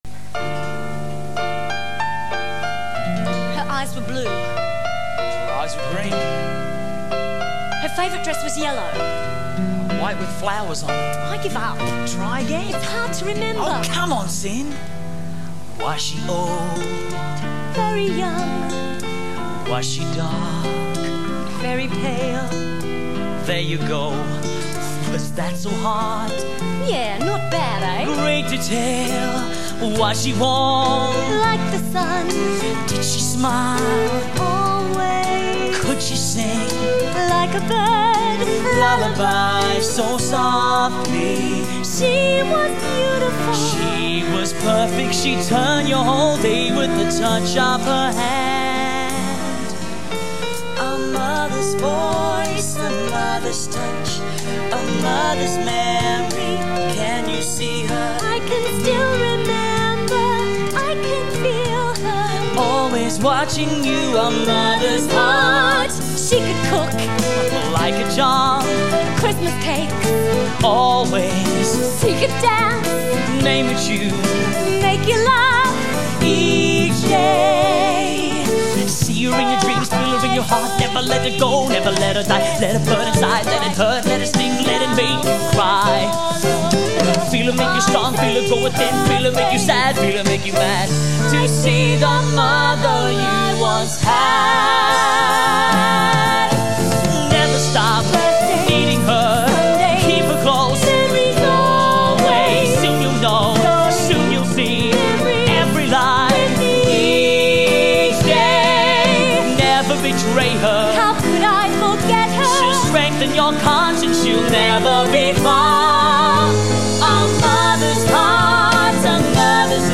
a live recording